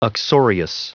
Prononciation du mot uxorious en anglais (fichier audio)